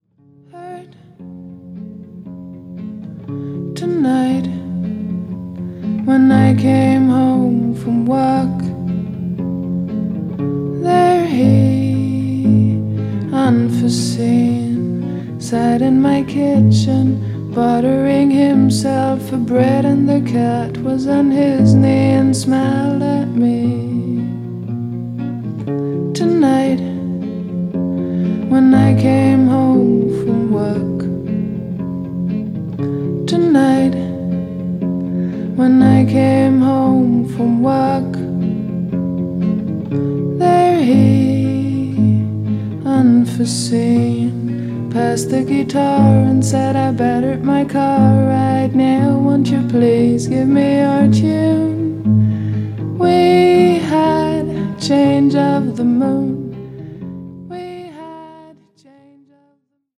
プライベート録音ならではの虚飾のない、くすんだトーンが聞くものの心に沁み入るような14曲。